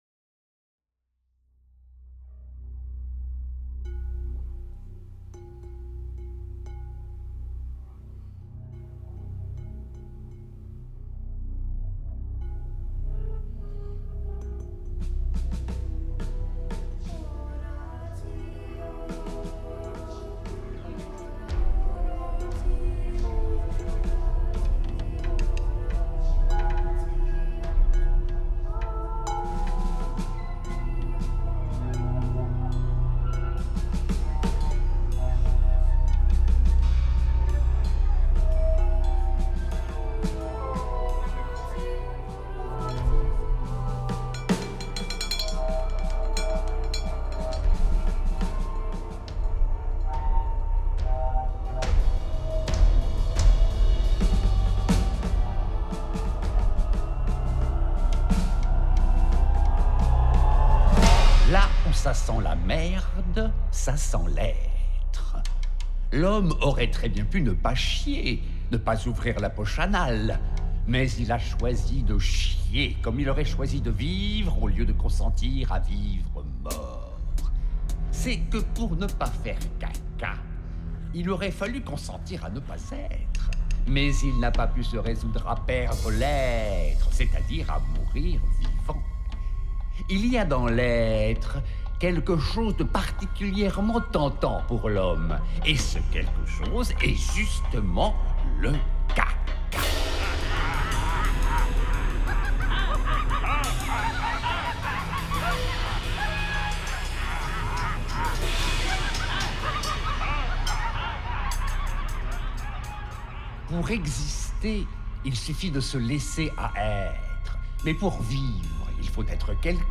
Poèmes dits par :